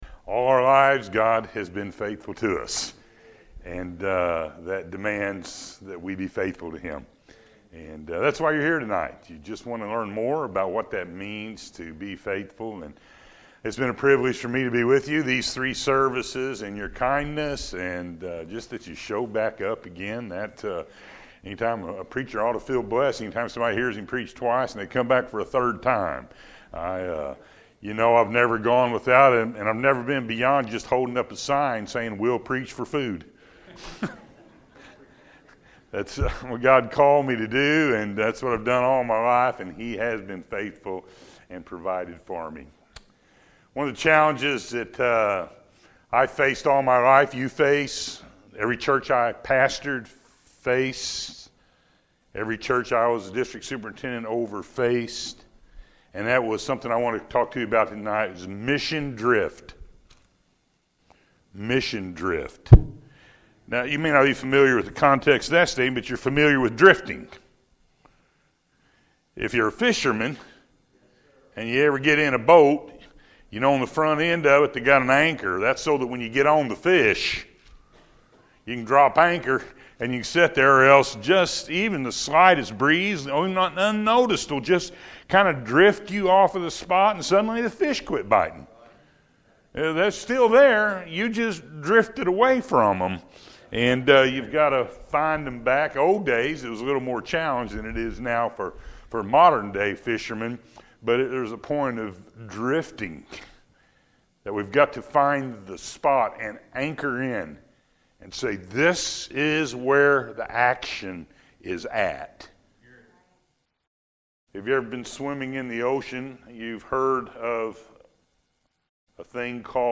Mission Drift-Revival Service